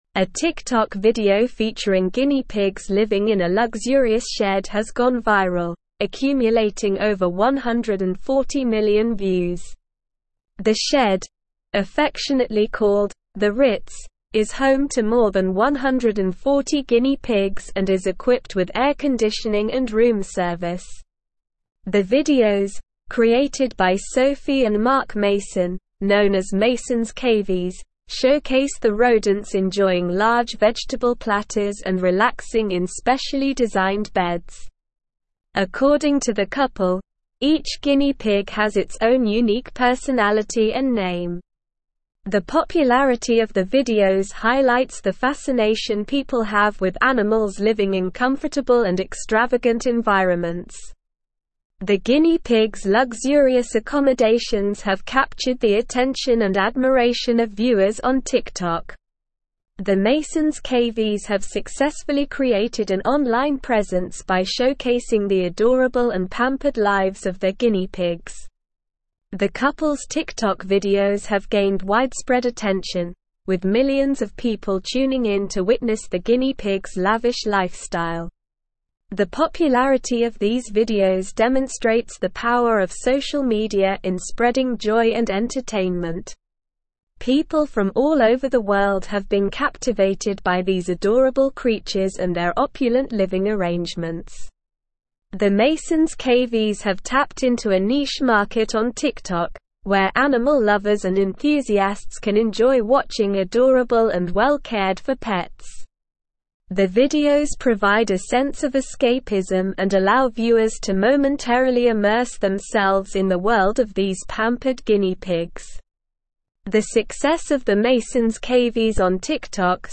Slow
English-Newsroom-Advanced-SLOW-Reading-Guinea-pigs-luxury-shed-goes-viral-on-TikTok.mp3